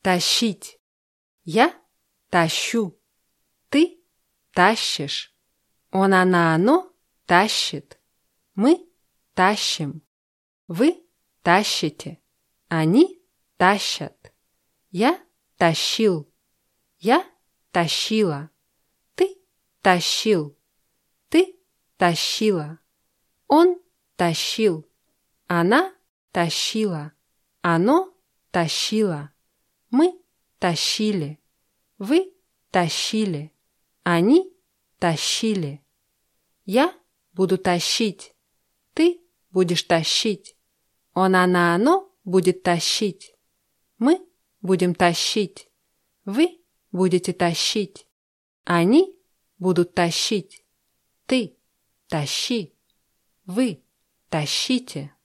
тащить [taschtschʲítʲ]